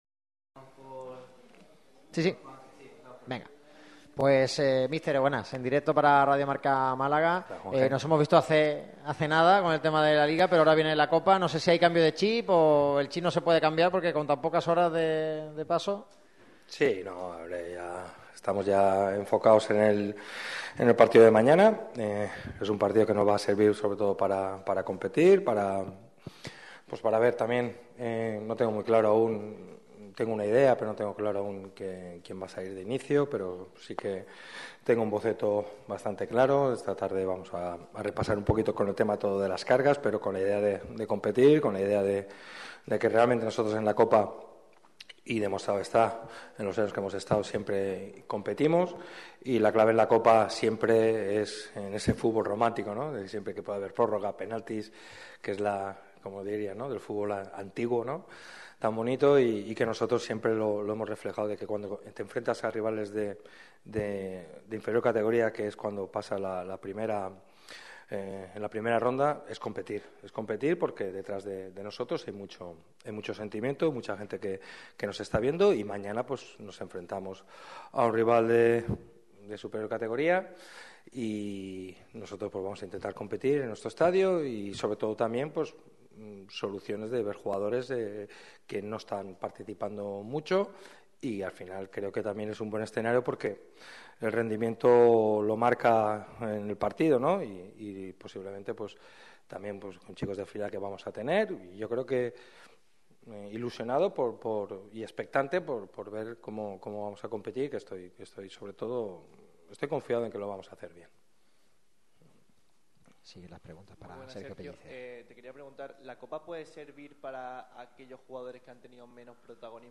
El técnico malaguista vuelve a pasar por los micrófonos de la sala de prensa de La Rosaleda con motivo de la previa del duelo de Copa del Rey ante el CD Eldense. El conjunto malaguista afronta este partido como un puro trámite ya que se centra en el encuentro en Mérida.